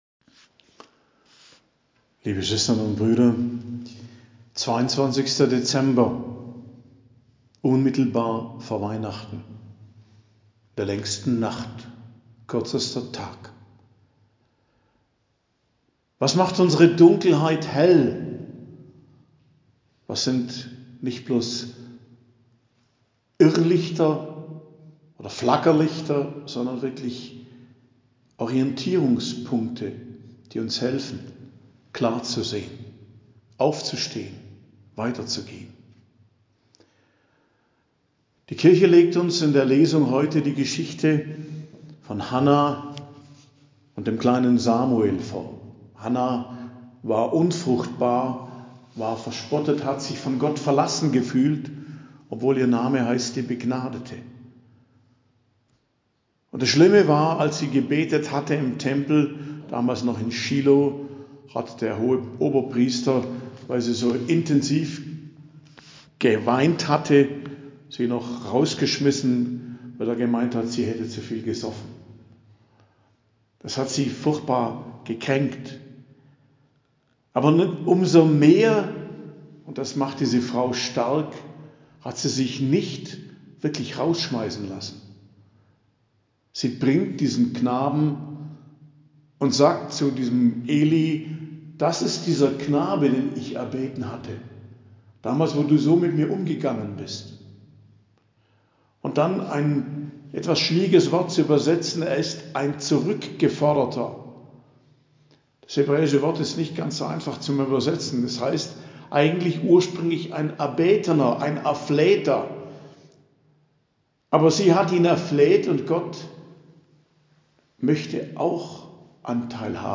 Predigt am Freitag der 3. Woche im Advent, 22.12.2023